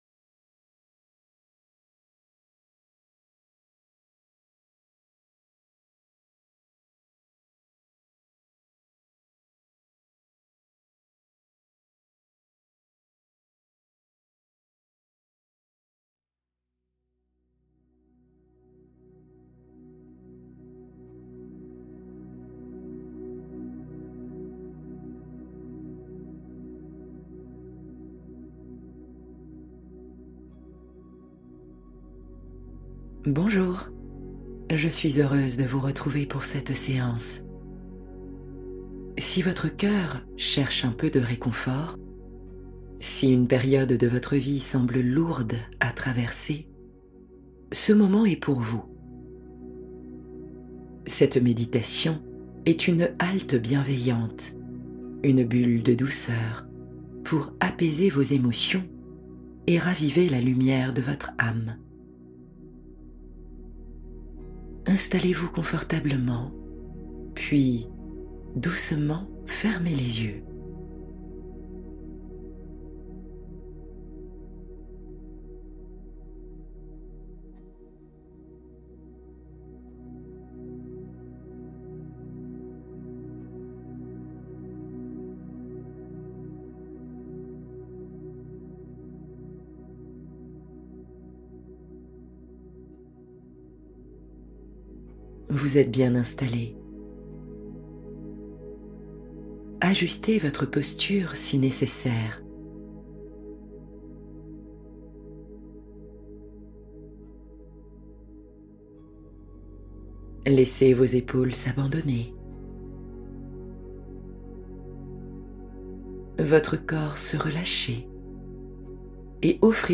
Relaxation pour se sentir bien dans sa peau et rayonner